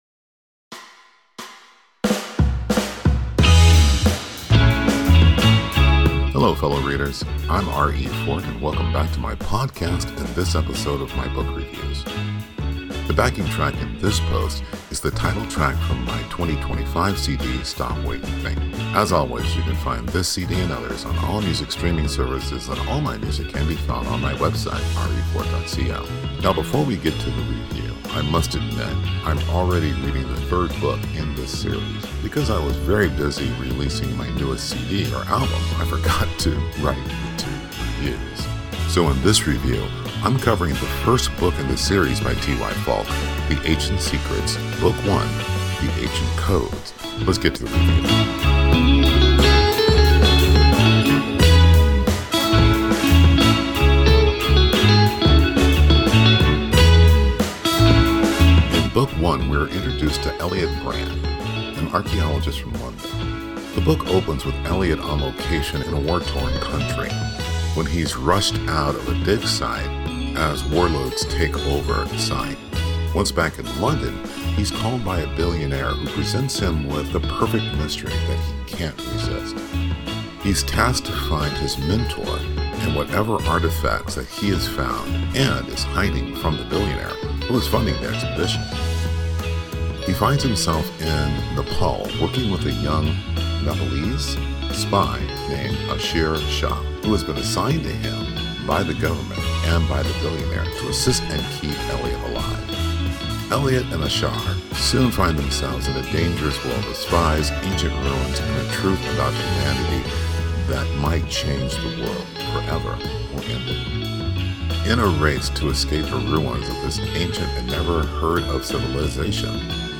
Podcast-Book-Review-The-Ancient-Code.mp3